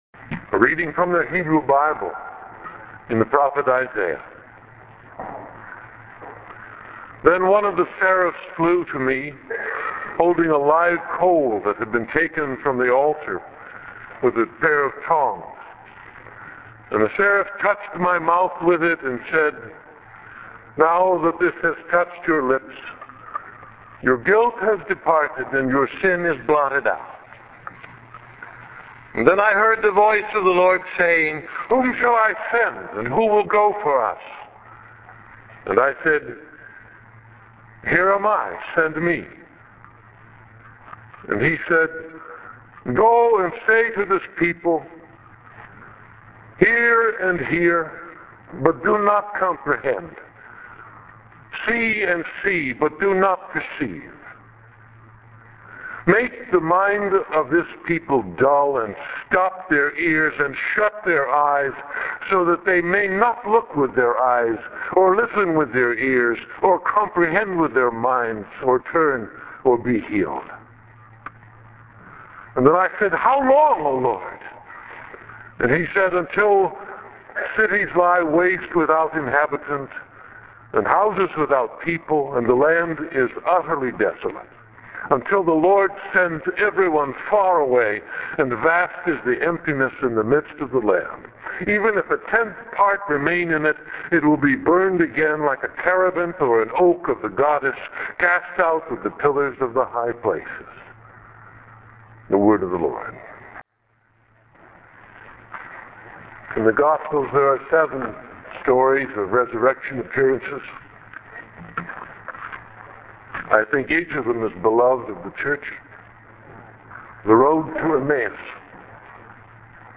download a PDF of this text listen to this sermon Texts on Sunday, April 6, 2008 I saiah 6: 6-13 ; Luke 24: 13-35 Those first disciples are pretty happy about Jesus’ resurrection.